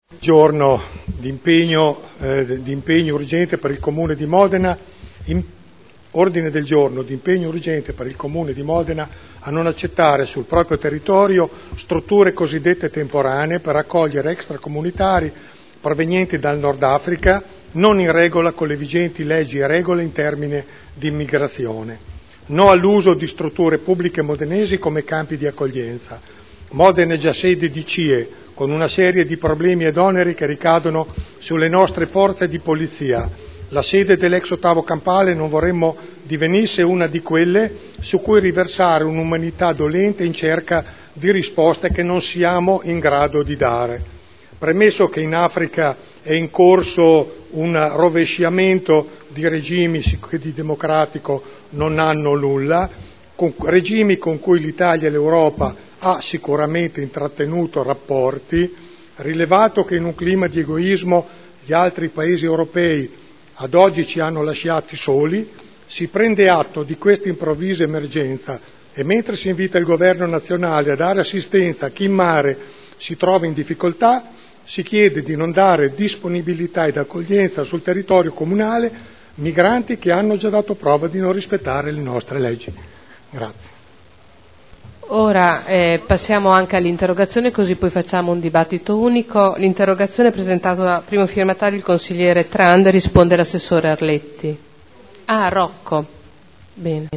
Seduta del 28 aprile 2011